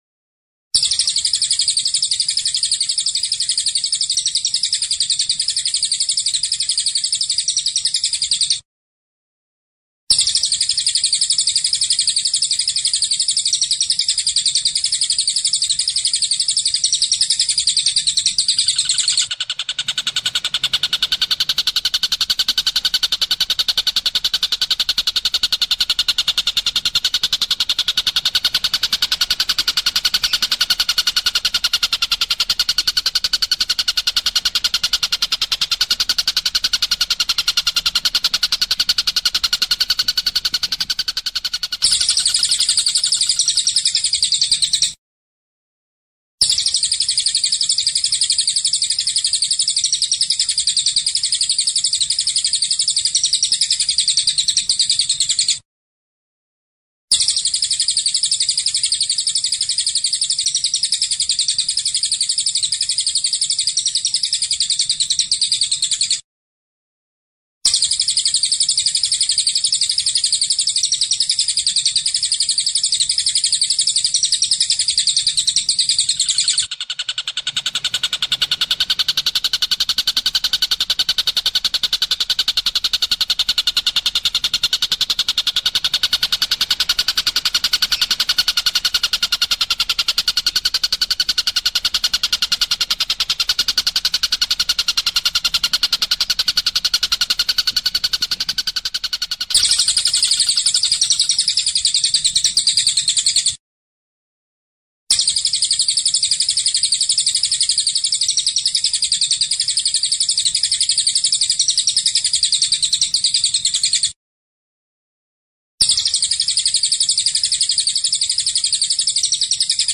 Download suara masteran Tengkek buto vs cililin gacor, duel maut antara burung cililin dan Tengkek buto. Suara jernih dan mantap dalam format MP3, ideal untuk melatih burung agar lebih rajin bunyi.
Suara Burung Tengkek Buto vs Cililin
suara-burung-tengkek-buto-vs-cililin-id-www_tiengdong_com.mp3